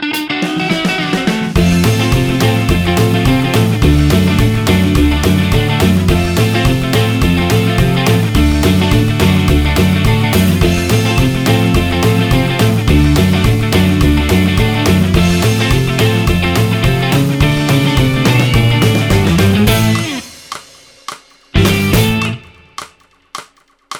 no Backing Vocals Rock 'n' Roll 3:15 Buy £1.50